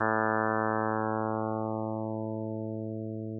Lets look into the software more closely and lets see about the demo's and getting more of them working.  2 very short sample sounds are included on the Left side Menu Bar.